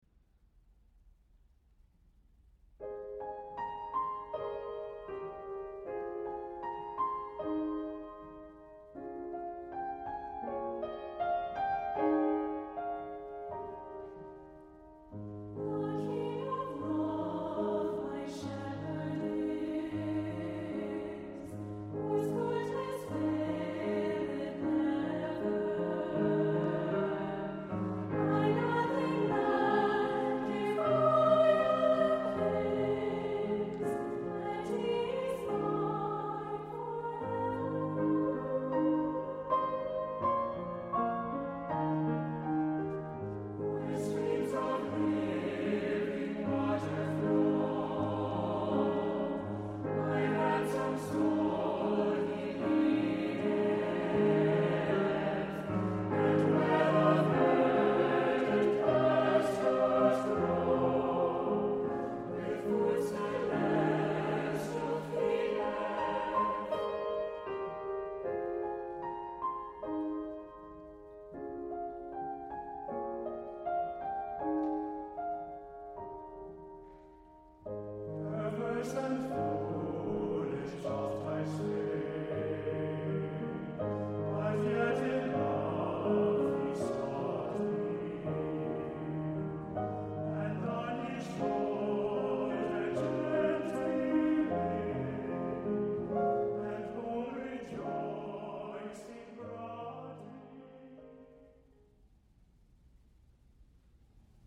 • Music Type: Choral
• Voicing: SATB
• Accompaniment: Piano